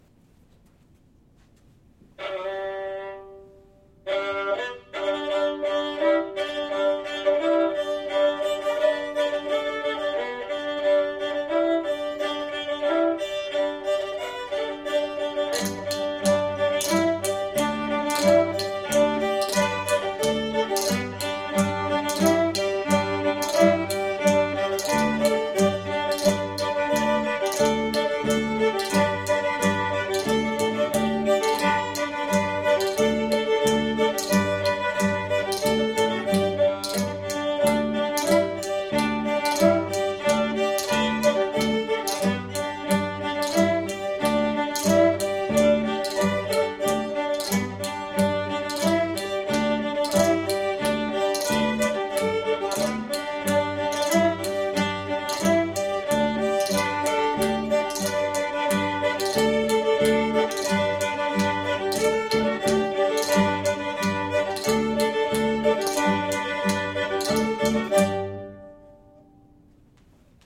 cajun-fiddle-tune.mp3